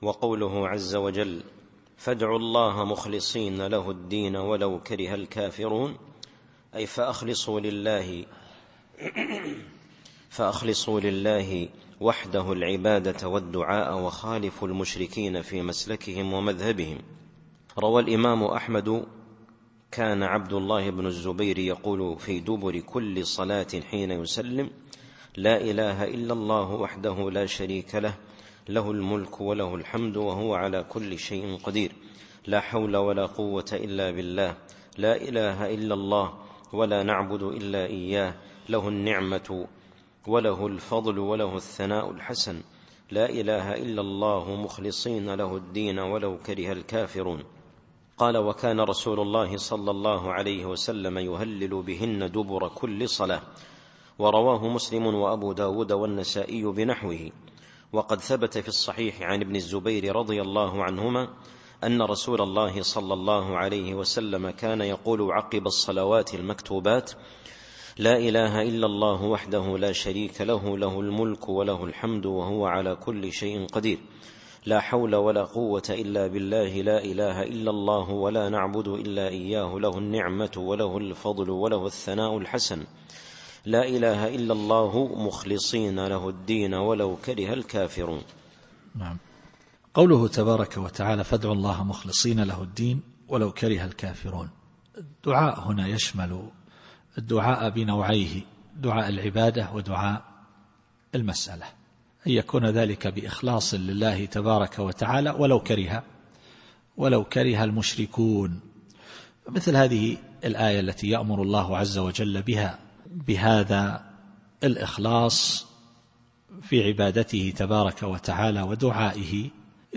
التفسير الصوتي [غافر / 14]